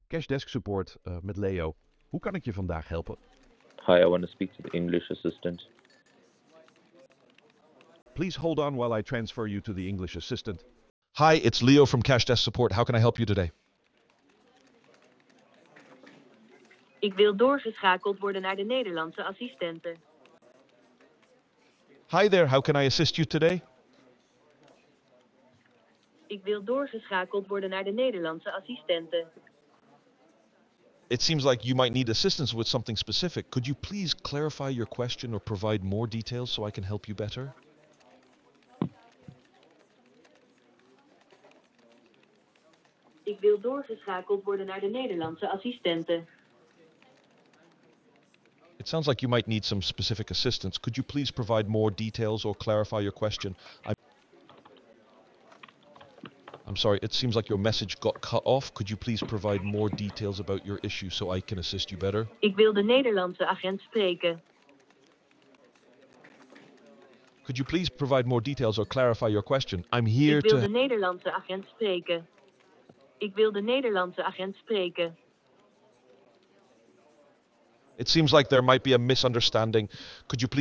Sometimes it works sometimes it doesn't. Like in this call, the Dutch agent transferred to the English agent but it didn't work the other way around.